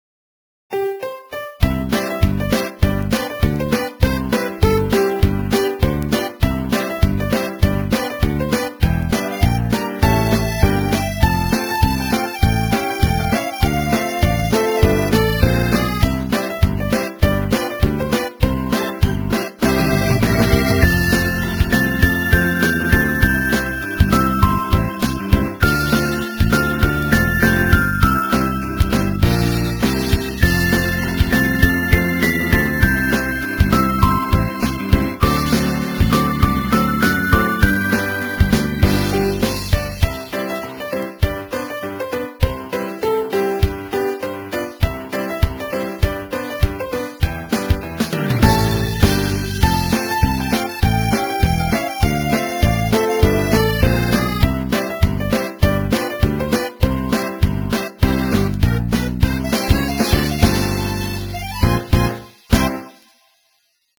纯音乐 - 入学欢快背景音乐